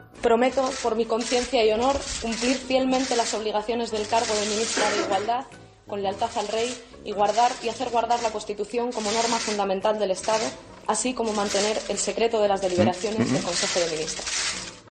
Momento en el que Irene Montero jura su cargo como ministra